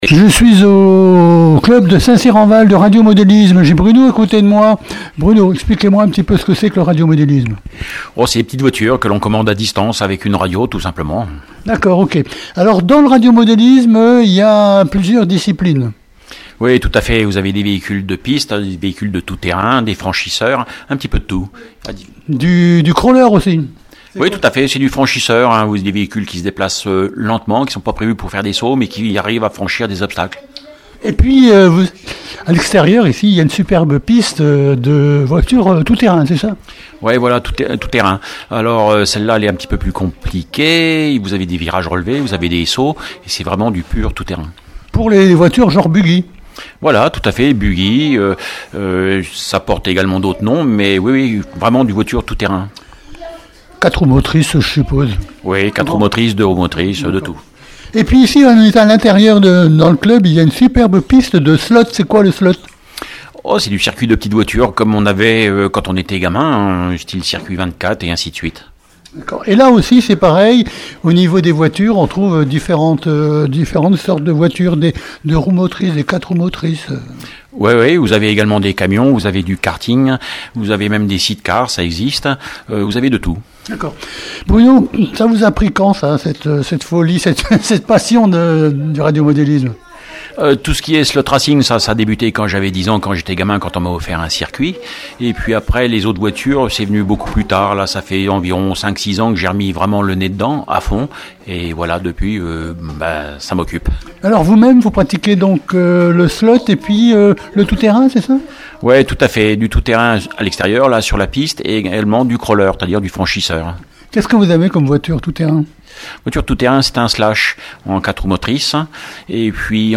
VAG MUSIC- RADIOMODELISME INTERVIEW NO 1